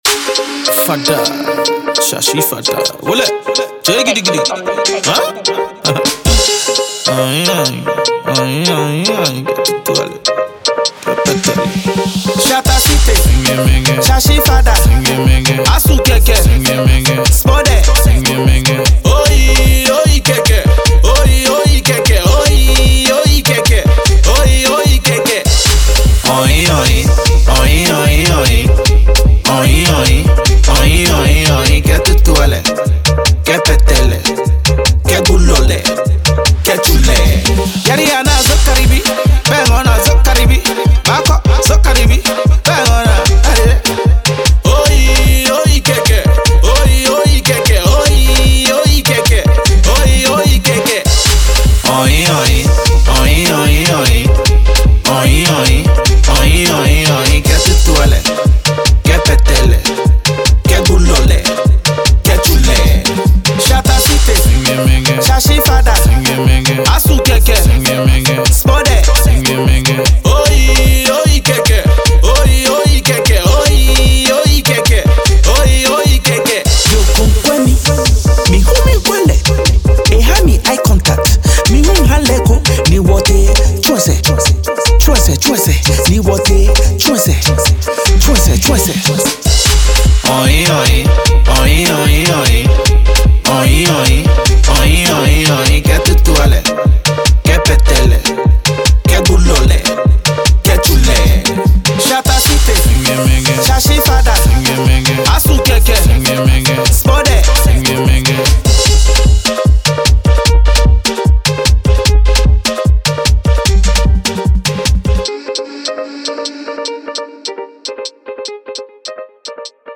Ghana MusicMusic
Veteran Ghanaian musician
new single